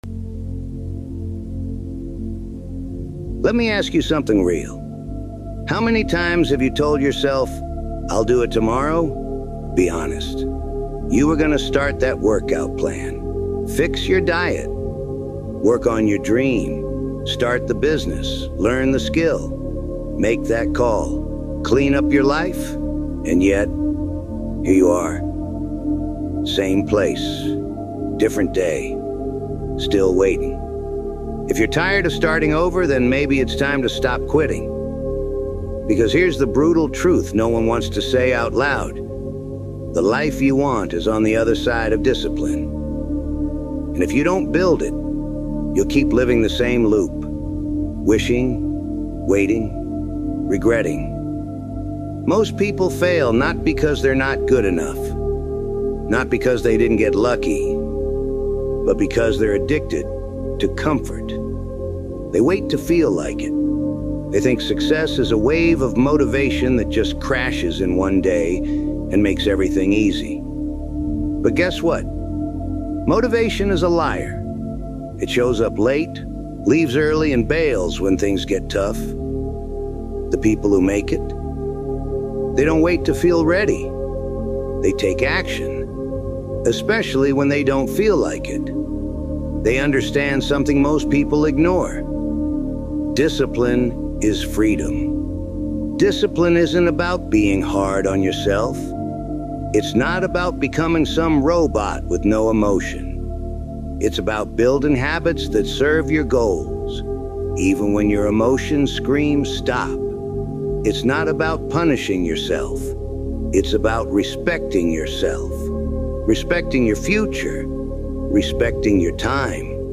Discipline Is Everything | Powerful Motivational Speech